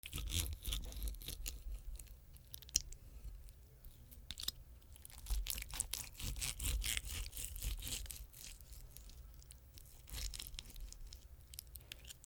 プラスチック食器で肉を切る 3
MKH416